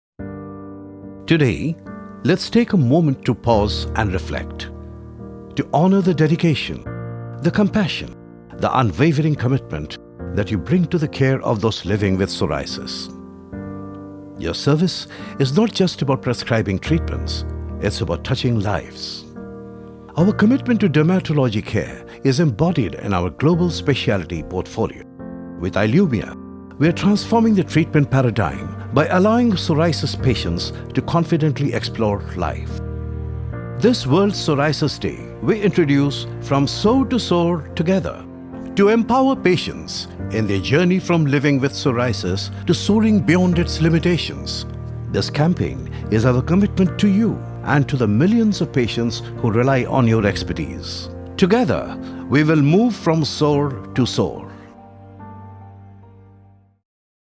English Voice Over Artist Male- english voice over male delhi NCR